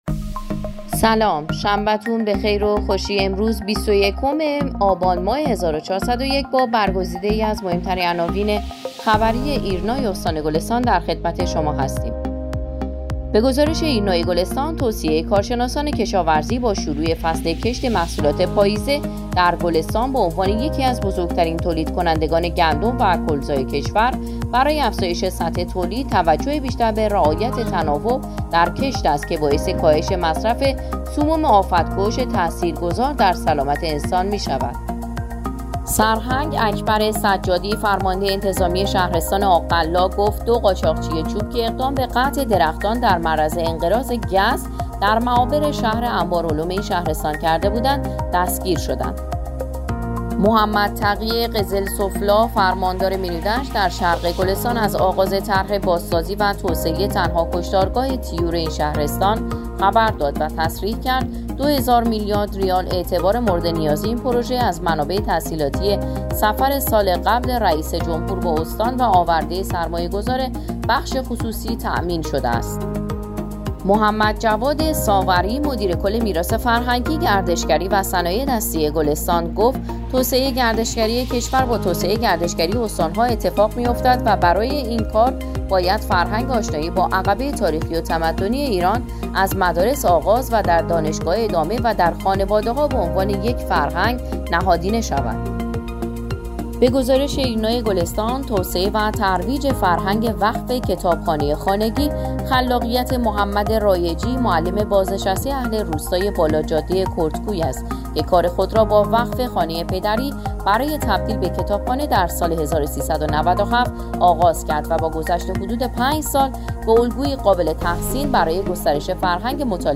صوت| اخبار شبانگاهی ۲۱ آبان‌ماه ایرنا گلستان